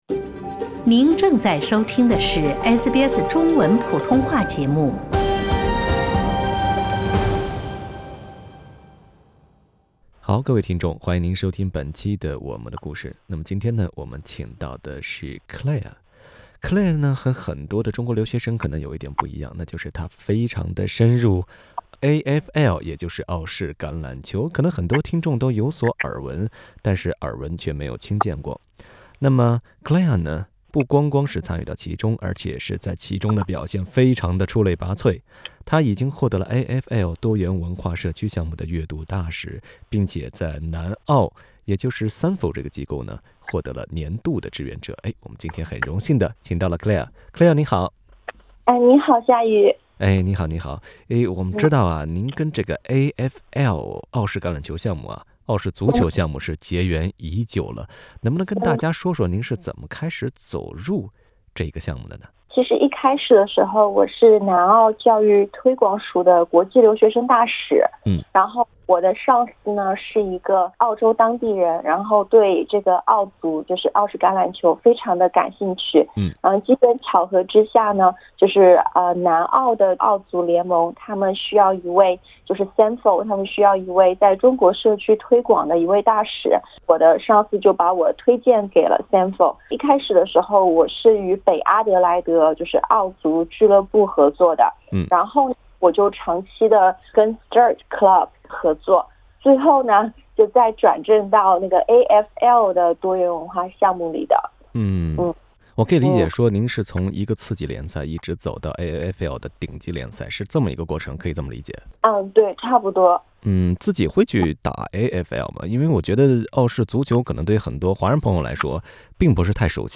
她在接受采访时表示，澳足是由早期移民因寻求身份认同而孕育出的体育运动。